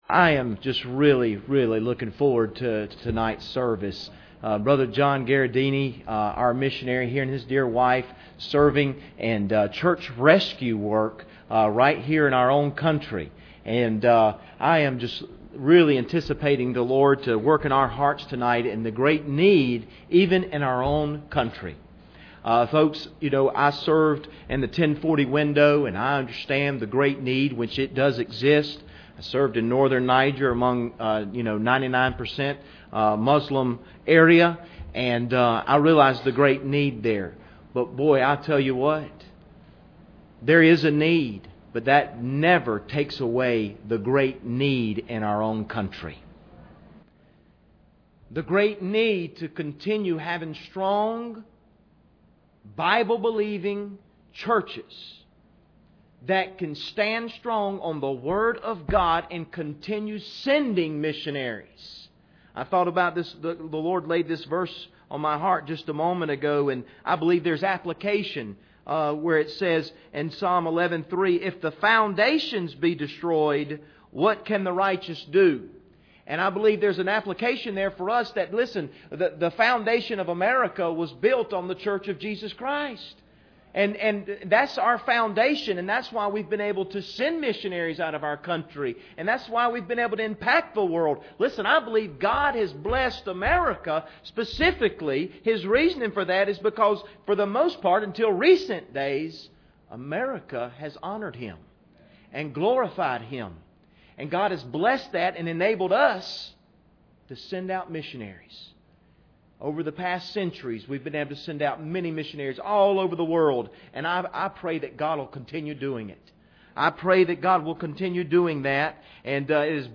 Series: 2016 Missions Conference
Service Type: Special Service